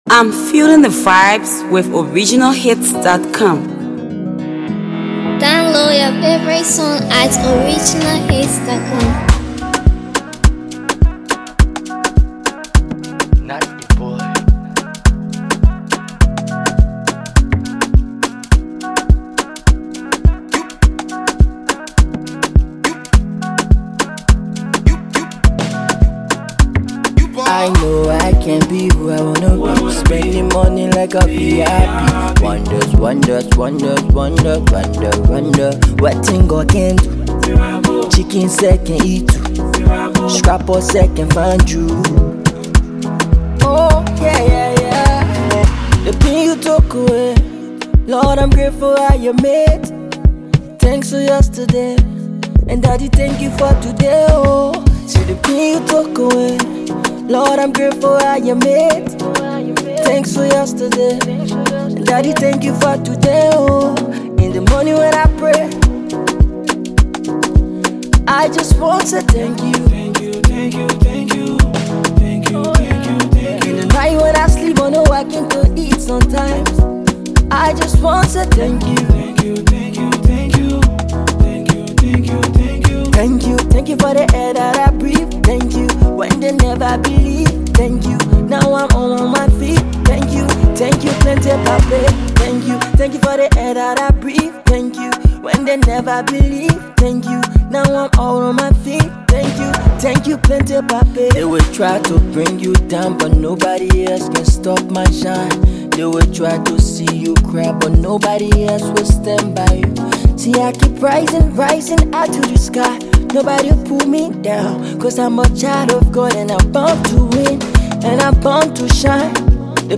soulful single
signature emotive vocals
Afro Pop
Gospel Music